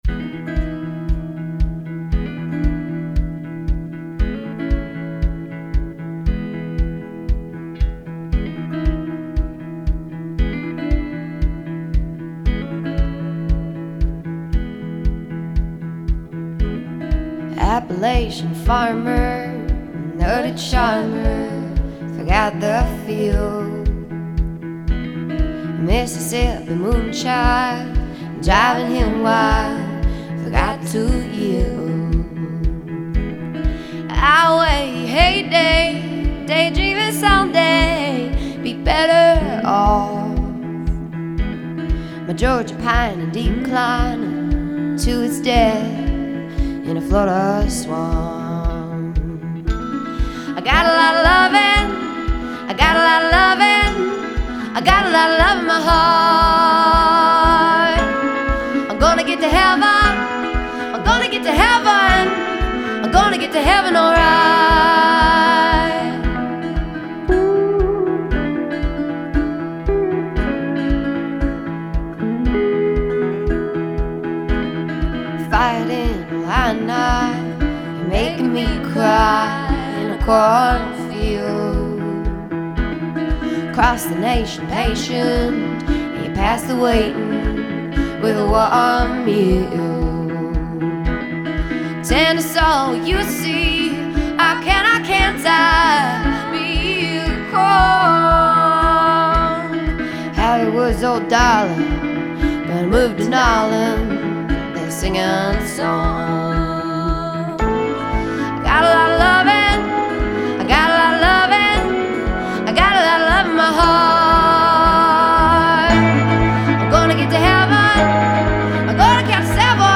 has a voice beyond her years.